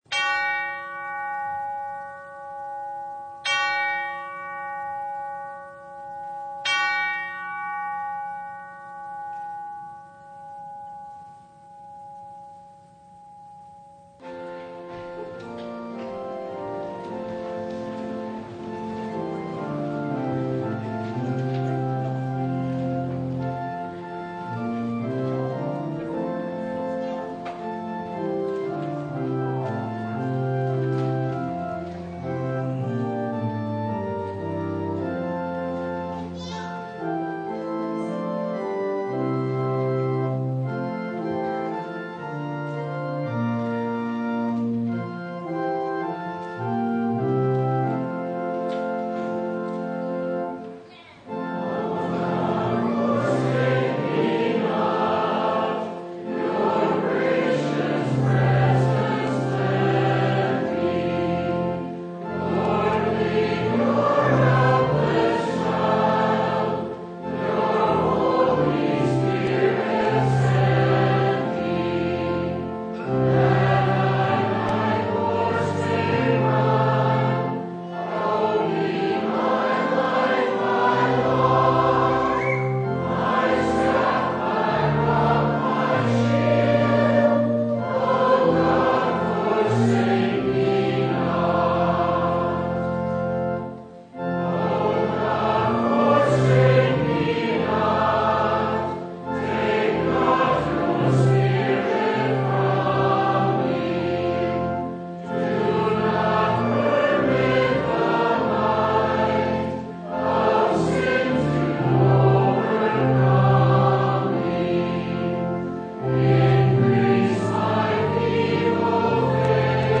Service Type: Sunday
Download Files Bulletin Topics: Full Service « A Good Shepherd in a Desolate Place “Take Heart; It Is I!”